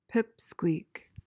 PRONUNCIATION: (PIP-skweek) MEANING: noun: Something or someone small or insignificant.